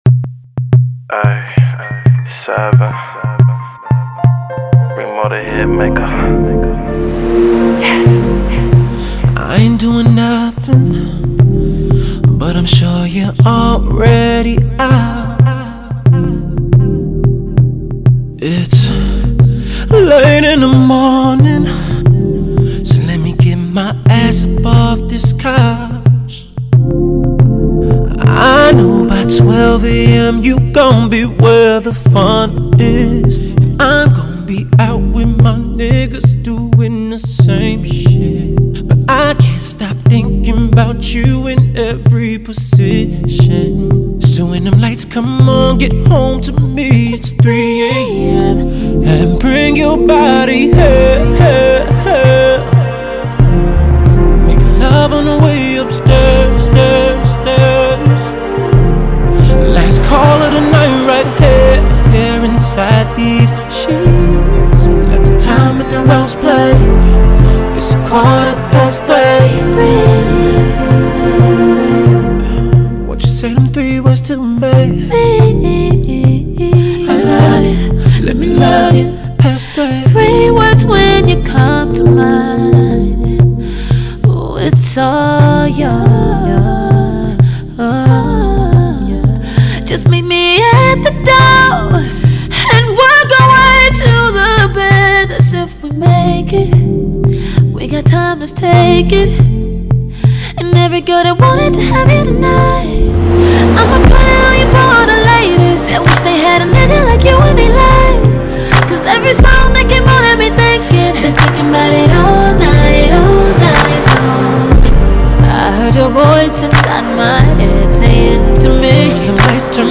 has a masculine but seductive tone.